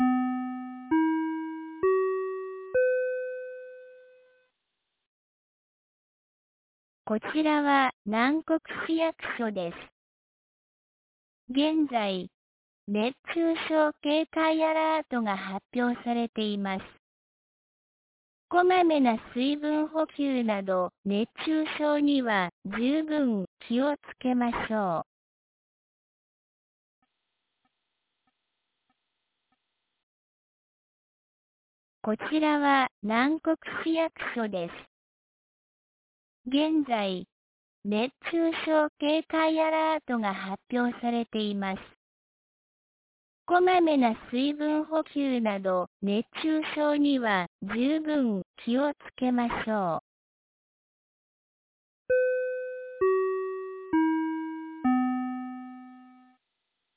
2024年07月23日 09時01分に、南国市より放送がありました。
放送音声